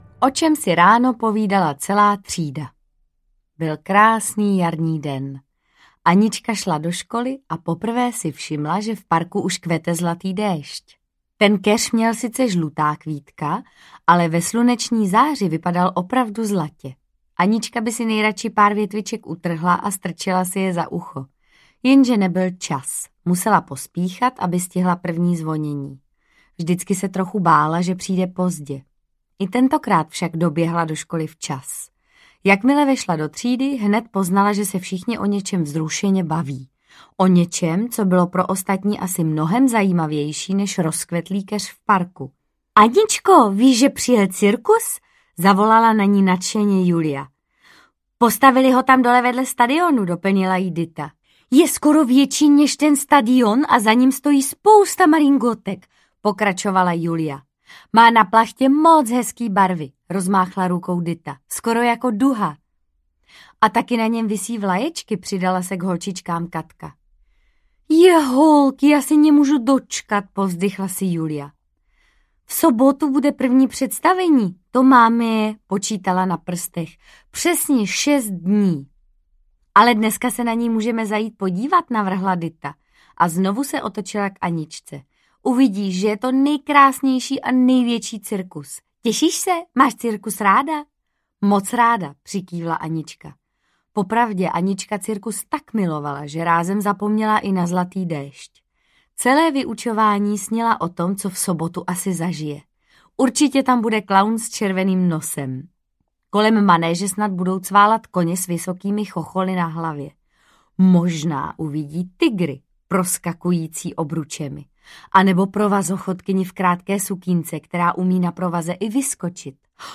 Anička a cirkus audiokniha
Ukázka z knihy
• InterpretMartha Issová